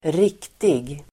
Uttal: [²r'ik:tig]